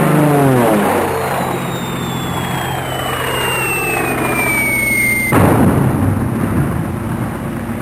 explosion 1